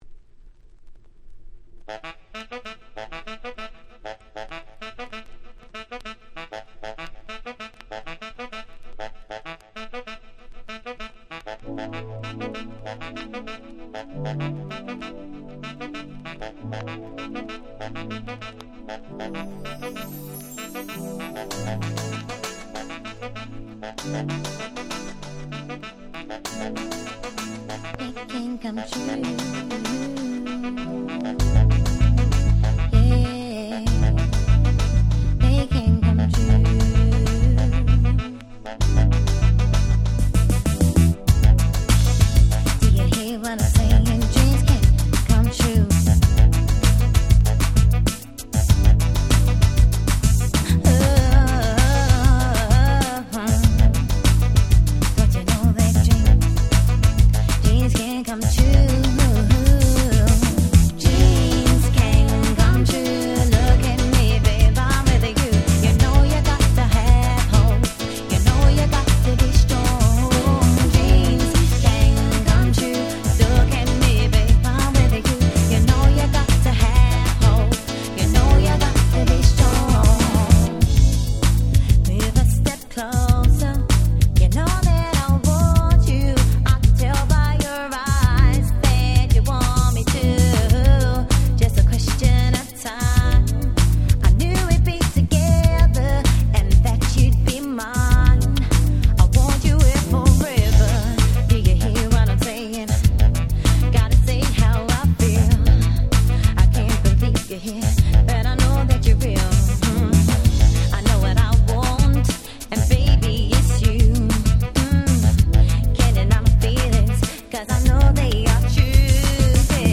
90's R&B